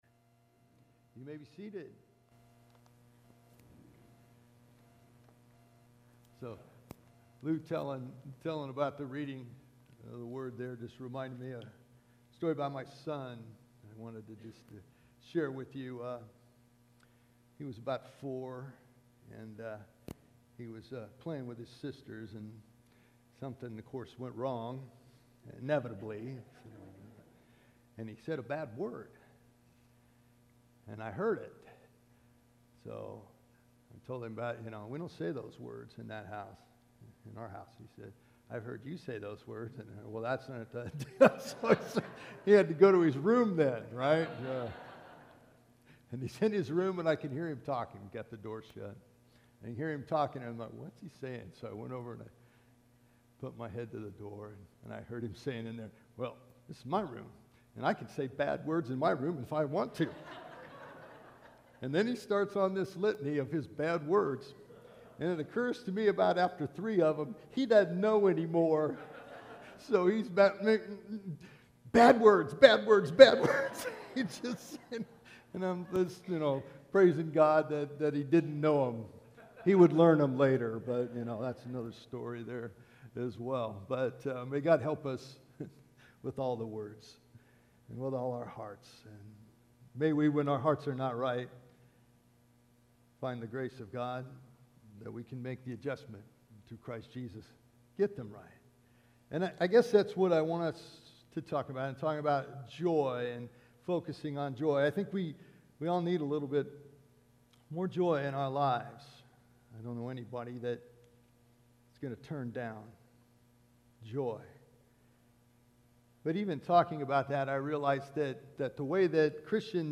Today we start a new sermon series on “Joy.” I thought it would be a good idea to begin this year with teaching about Joy – what it is, what it is not, how do we keep it, how do we keep others from stealing it and how do we hold onto it when our hearts are breaking. We’ll be looking for inspiration and guidance from Paul’s letter to the Church at Philippi.